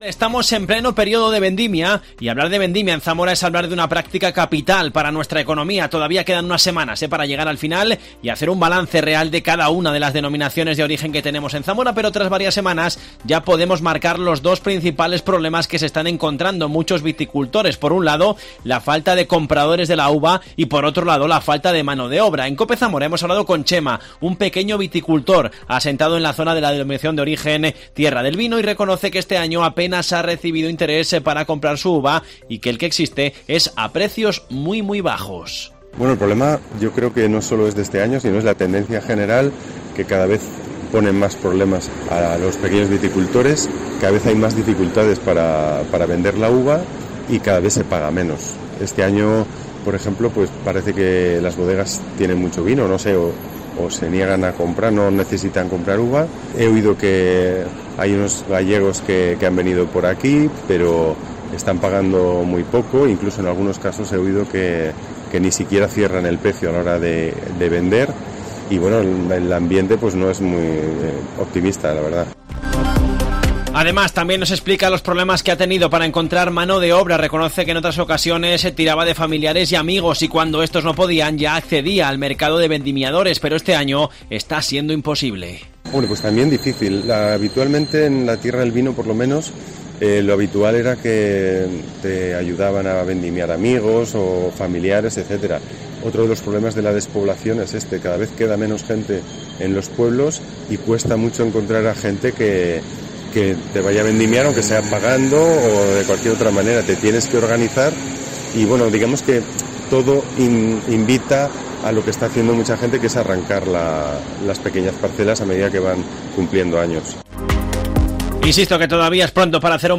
La catastrófica previsión de un viticultor de Zamora: “Cada vez más gente está arrancando las vides”